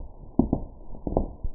footstepmuffled.wav